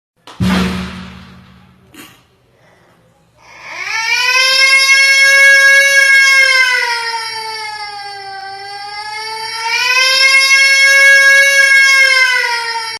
air raid siren (HD)